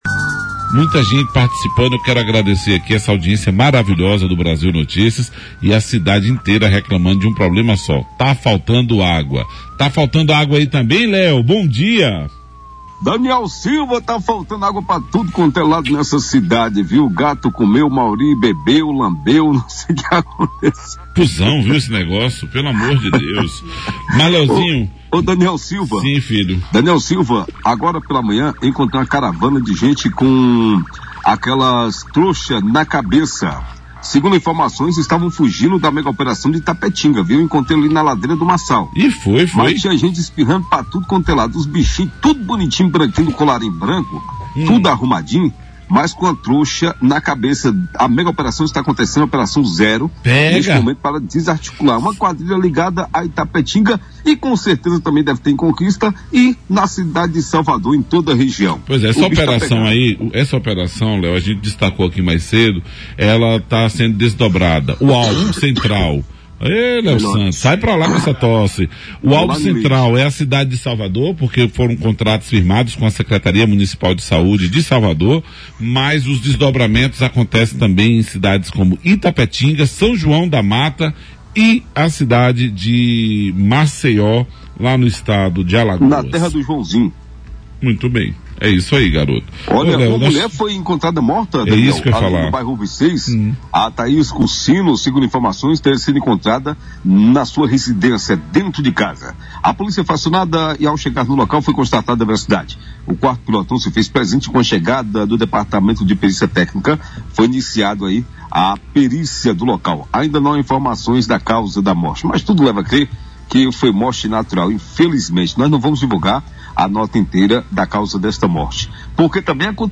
As últimas 24 horas foram marcadas por diversas ocorrências policiais em Vitória da Conquista. Na reportagem do Brasil Notícias desta quinta-feira (12), duas mortes ganharam destaque: uma no Parque Comveima I e outra no Conjunto Habitacional URBIS VI.